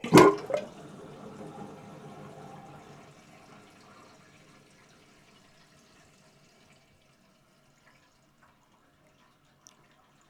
household
Sink Draining with Water Rushing Down